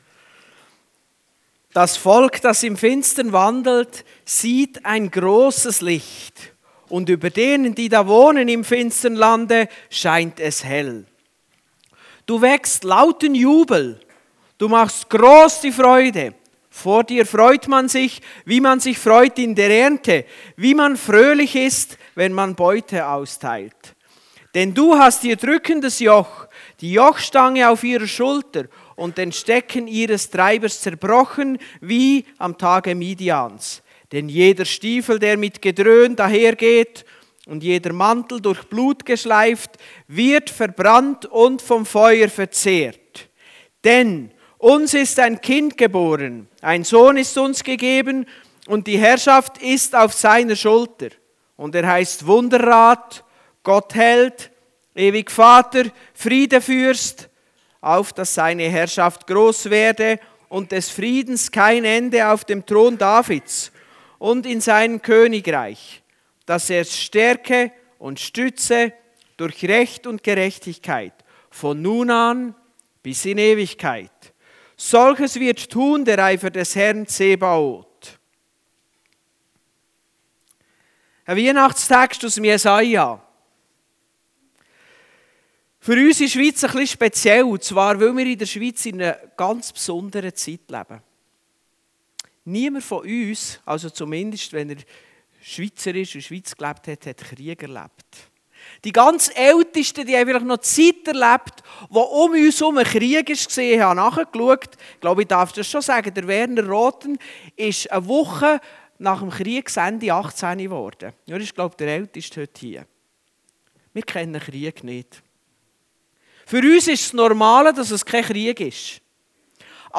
Passage: Jesaja 9, 1-6 Dienstart: Gottesdienst